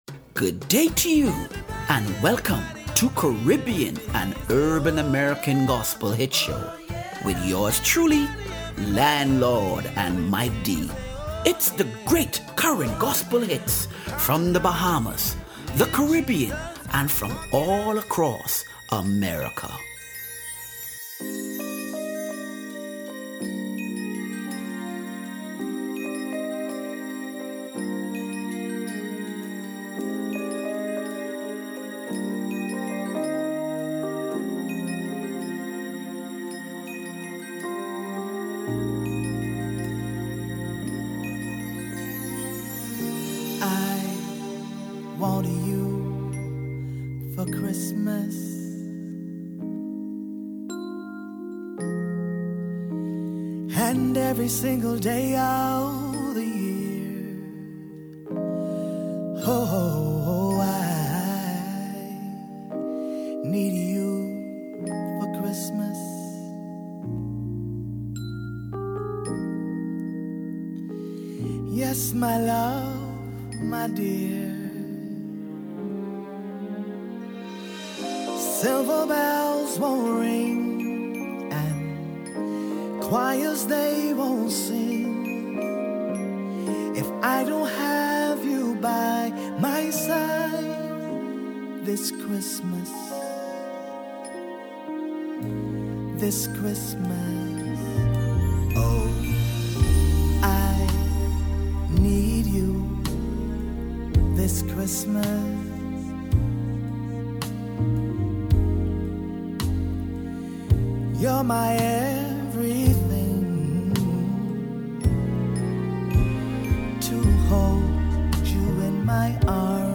Caribbean and Urban American Gospel Hits - November 30 2025